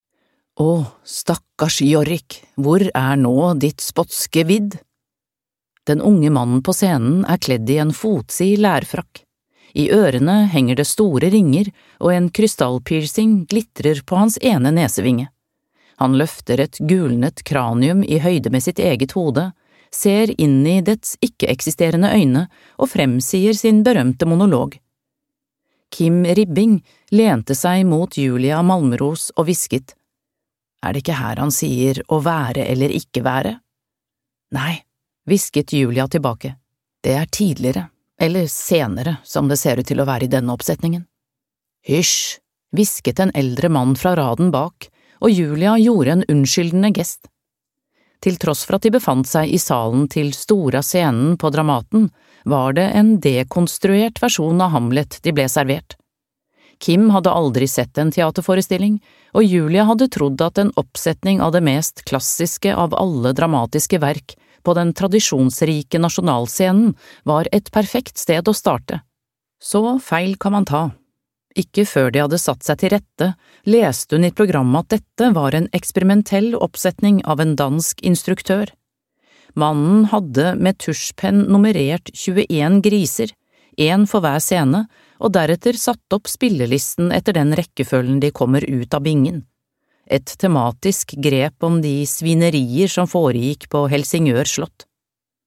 Ånden i maskinen (lydbok) av John Ajvide Lindqvist